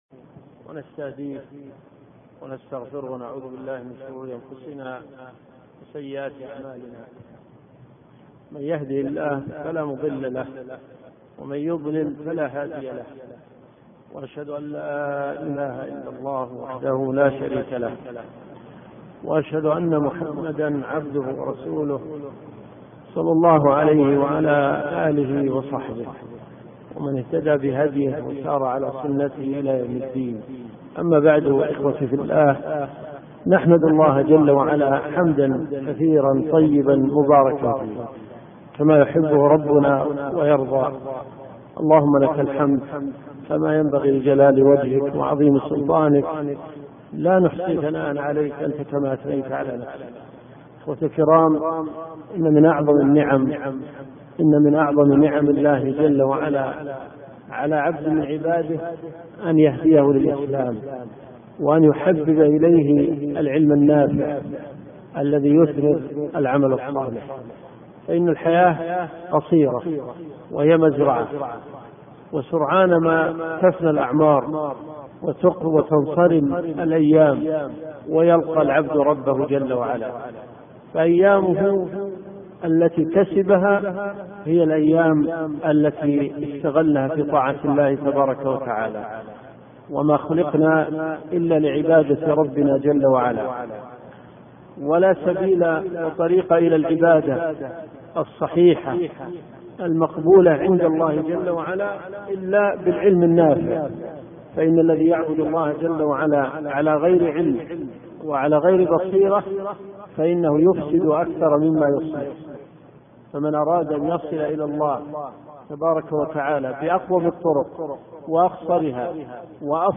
الرئيسية الدورات الشرعية [ قسم العقيدة ] > لمعة الاعتقاد . 1426 .